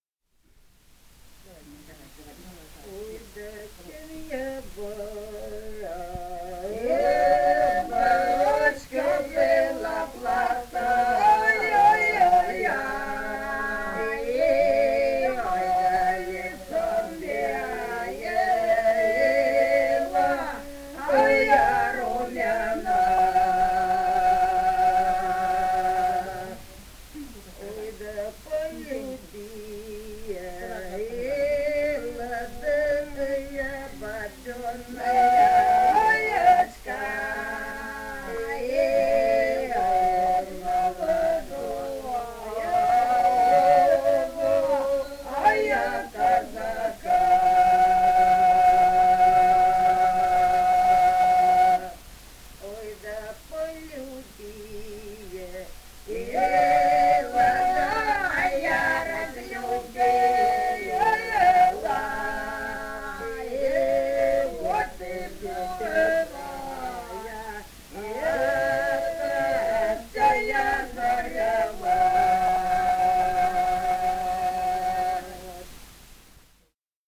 полевые материалы
Ростовская область, ст. Вёшенская, 1966 г. И0938-12